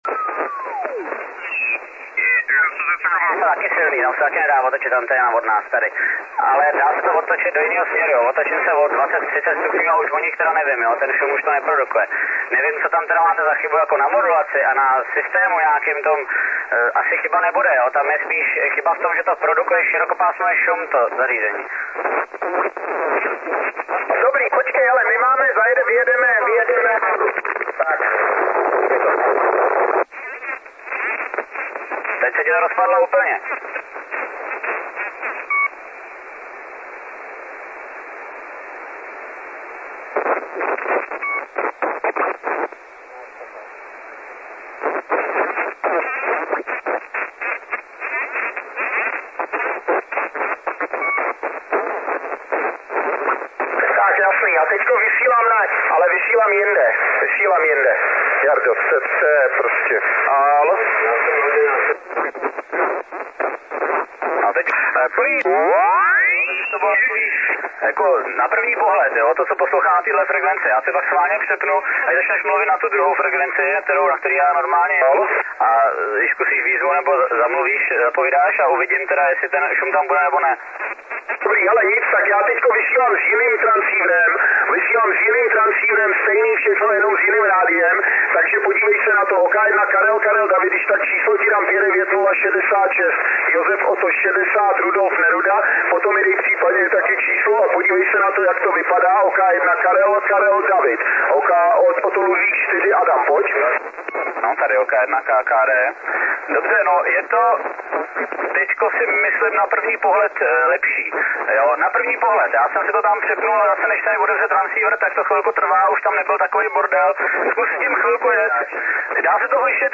Asi ve 2 MHz segmentu slyším příšerné chroustání od OL4A a stanice, které jsou ode mne vzdušnou čárou 80-100 km musím několikráte prosit o zopakování předávaného kódu.
rušení OL4A_2.mp3 (1.1 MB)